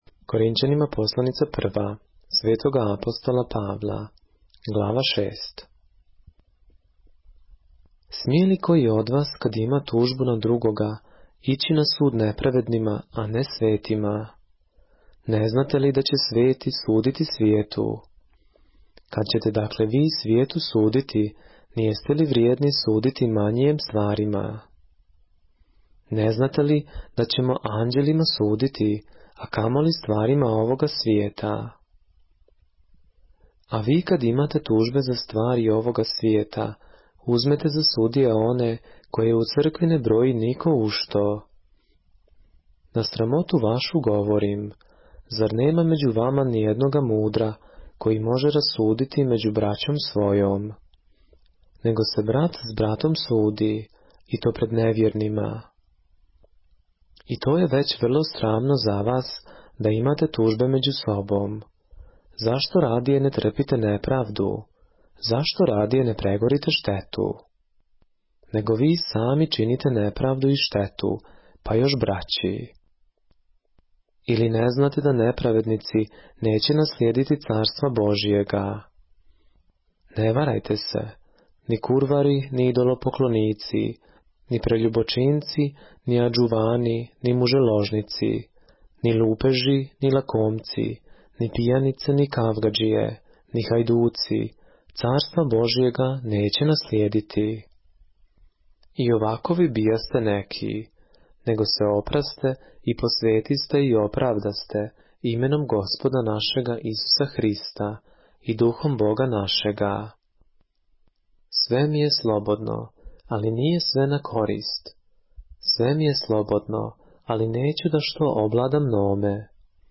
поглавље српске Библије - са аудио нарације - 1 Corinthians, chapter 6 of the Holy Bible in the Serbian language